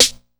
Snares
JJSnares (1).wav